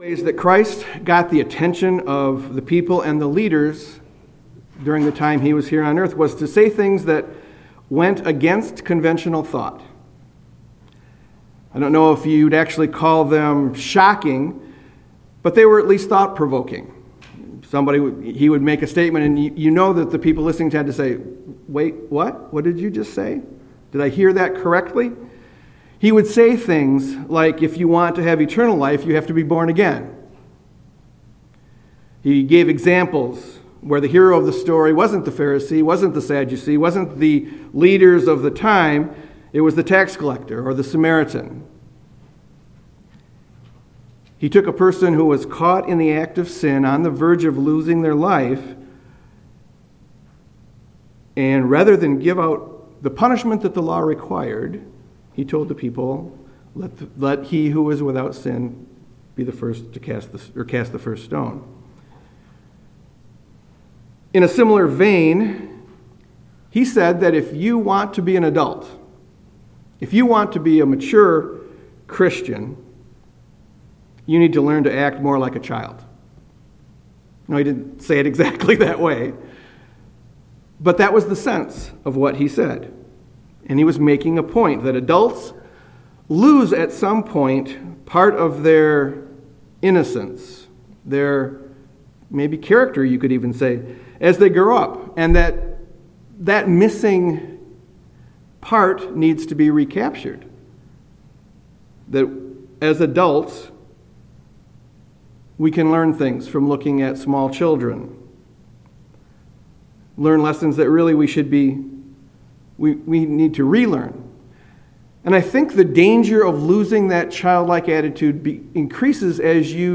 Sermons
Given in Grand Rapids, MI